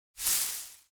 grass swish 2.ogg